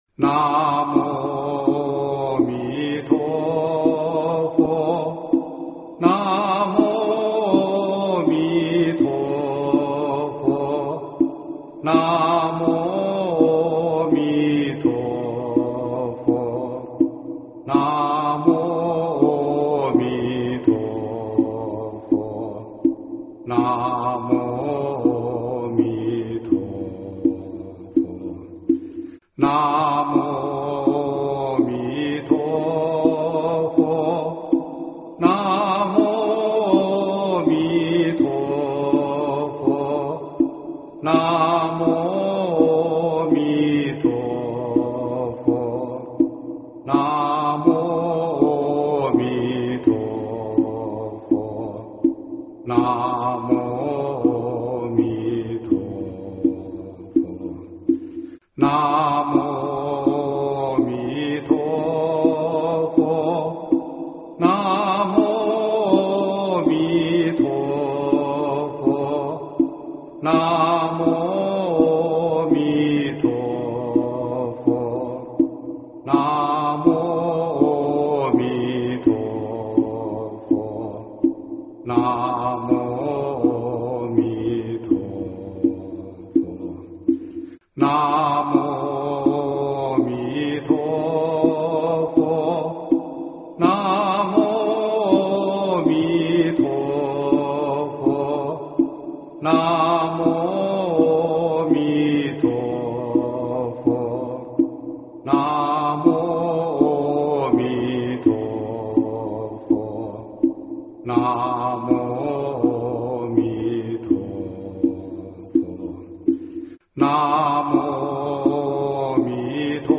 经忏
佛教音乐